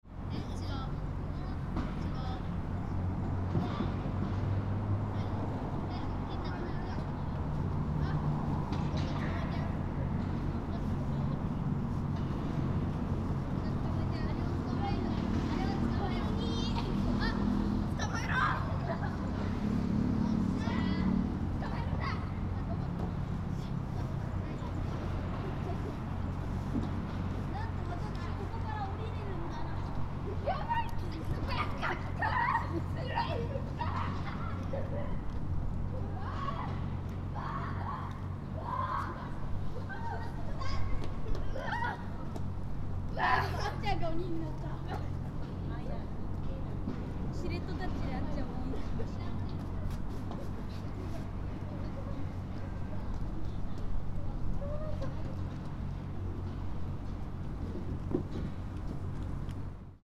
On a warmer day in January, around 10 children were playing cheerfully in the park. ♦ A few birds were twittering around this park.